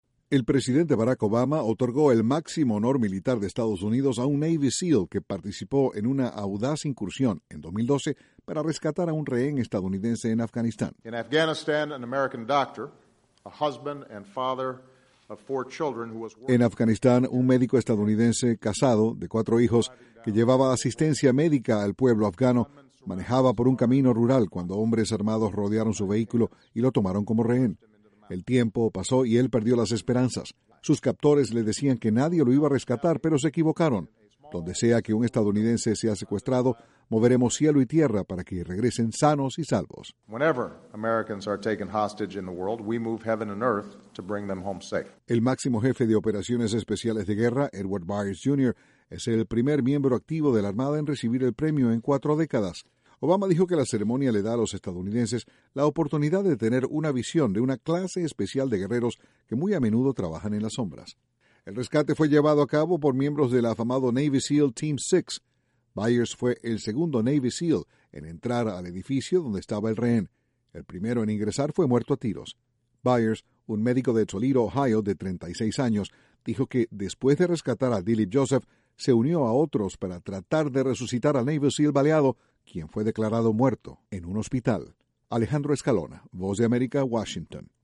Un Navy Seal que rescató a un rehén en Afganistán fue condecorado por el presidente de Estados Unidos. Desde la Voz de América, Washington, informa